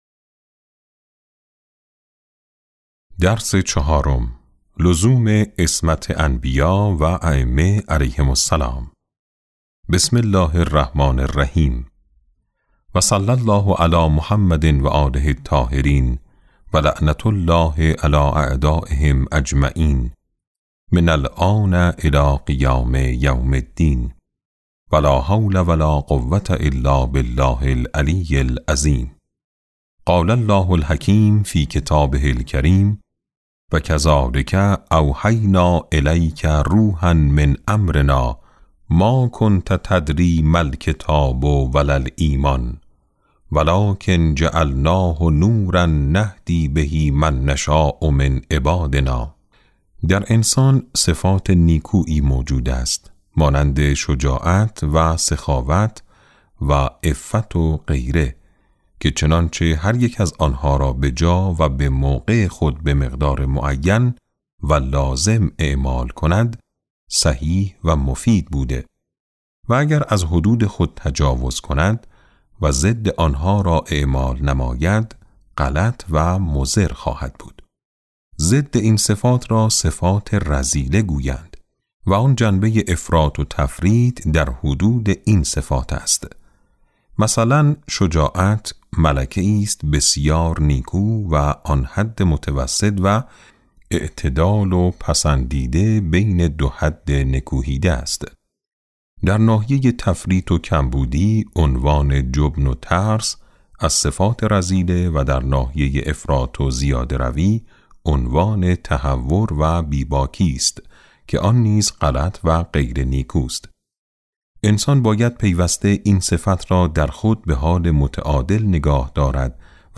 کتاب صوتی امام شناسی ج1 - جلسه6